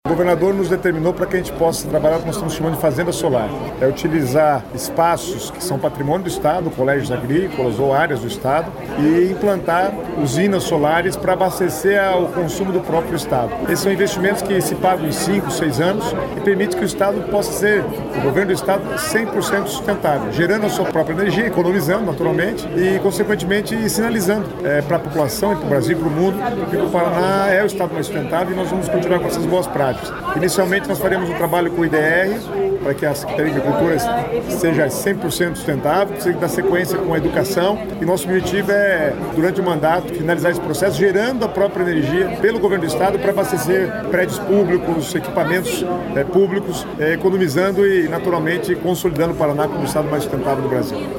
Sonora do secretário do Planejamento, Guto Silva, sobre o investimento de R$ 14,6 milhões para instalar seis parques solares para o IDR